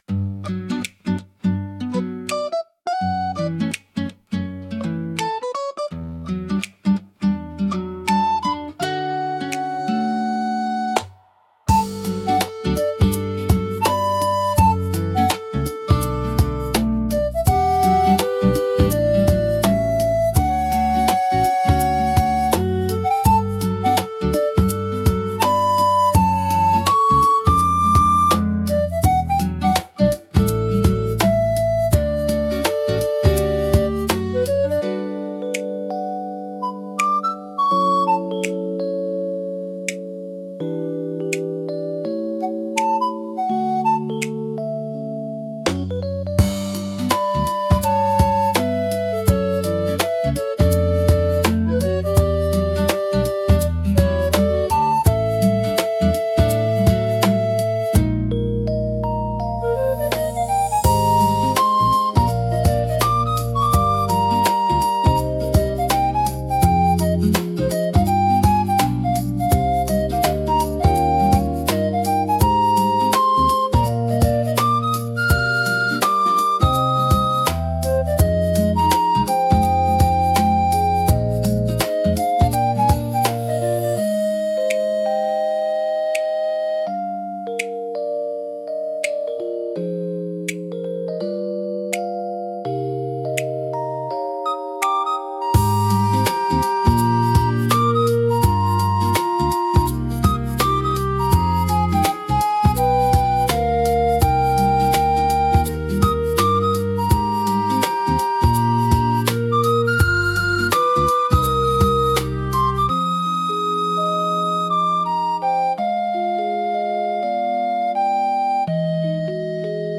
かわいい , ほのぼの , コミカル , ムービー , リコーダー , 朝 , 秋 , 穏やか